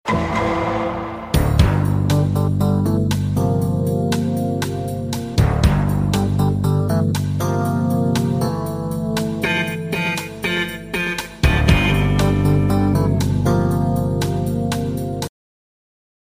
Meme DUN DUN and song